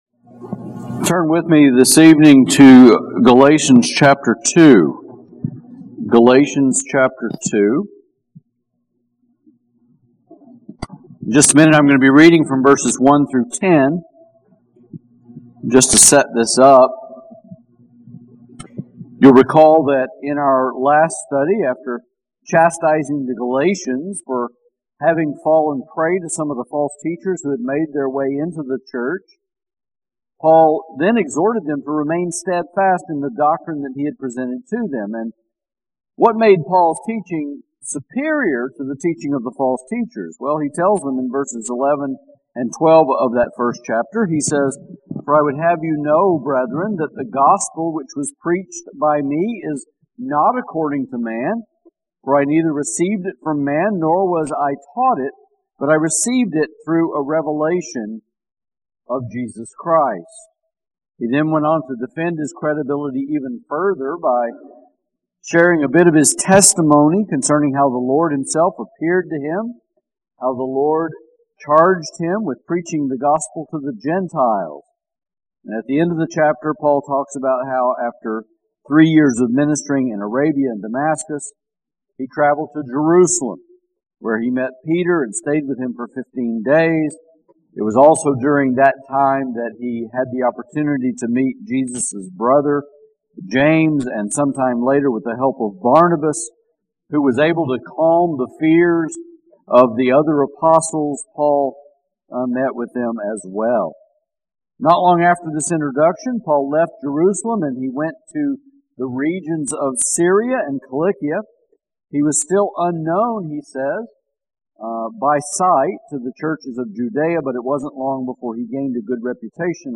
Sermons | Grace Baptist Church San Antonio